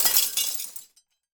glass_smashable_debris_fall_05.wav